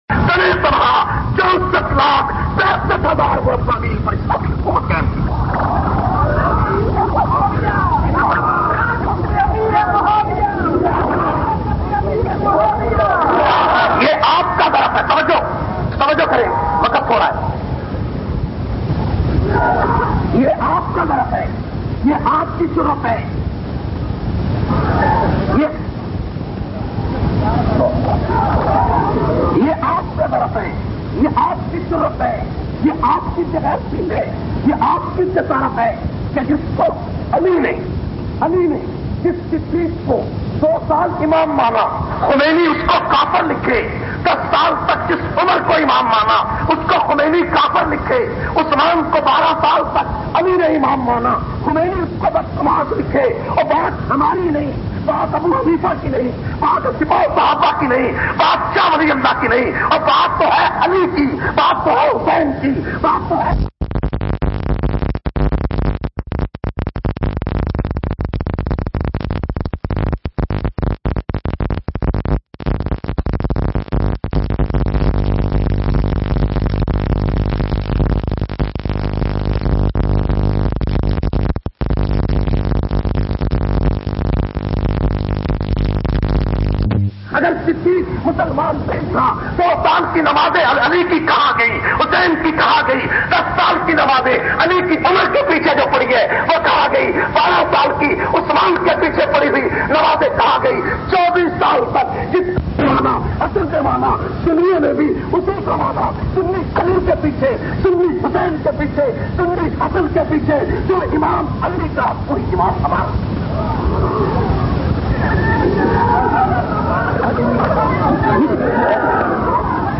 519- Sideeq e Akbar Conference Samandri Faisalabad.mp3